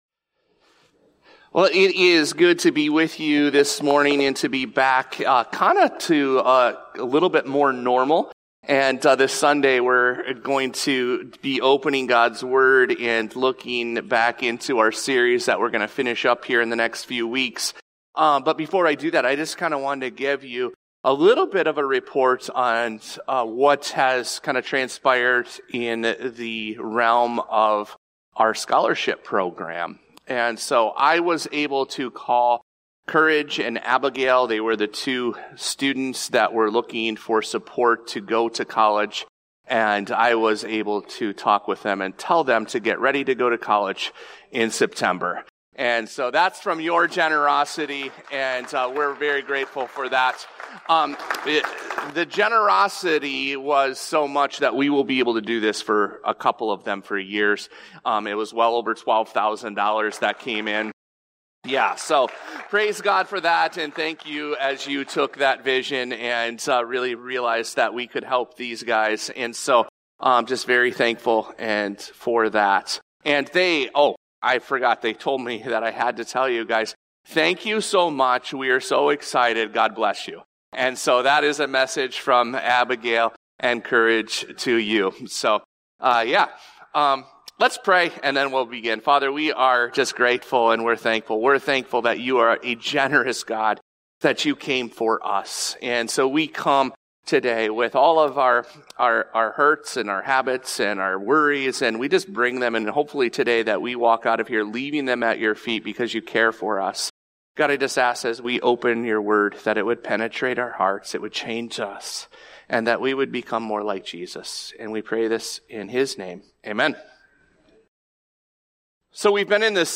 This podcast episode is a Sunday message from Evangel Community Church, Houghton, Michigan, January 12, 2025.